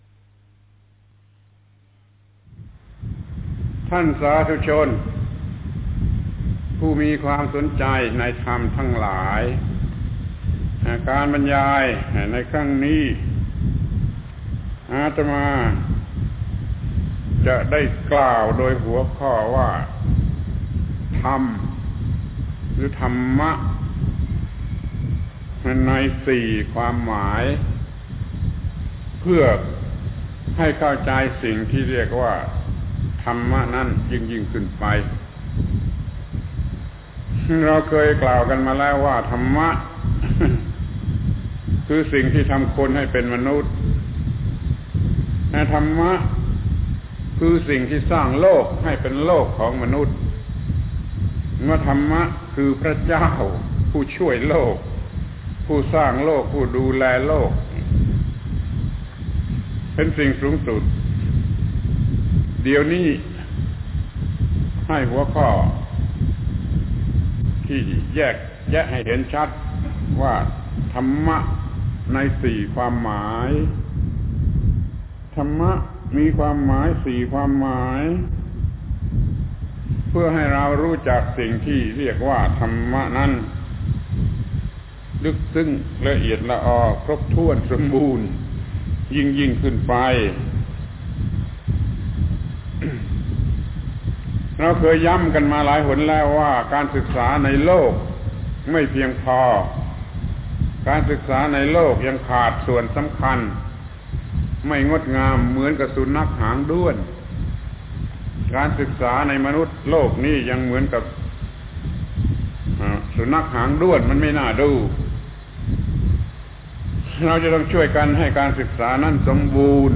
ปาฐกถาธรรม รายการพุทธธรรมนำสุข ครั้งที่ ๑ ครั้ง ๔ ธรรมะในสี่ความหมาย